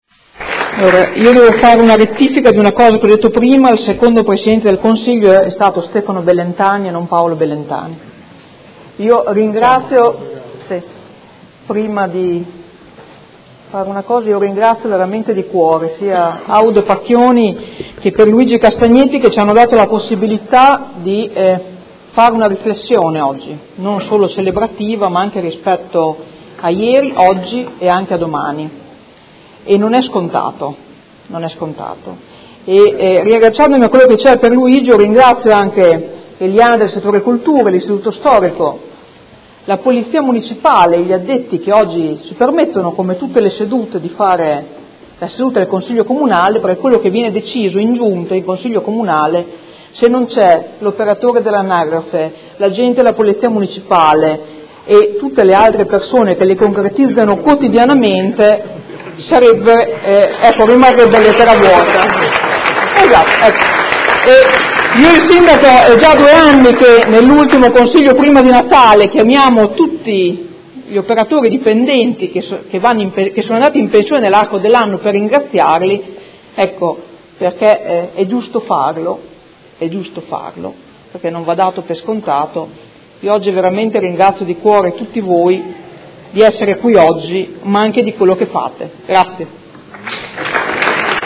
Seduta del 20/04/2016. Conclude dibattito su Celebrazione del 70° dall'insediamento del primo Consiglio Comunale di Modena dopo il periodo fascista.